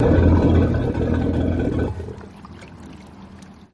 Boat Engine
V6 Chris Craft Boat Shut Off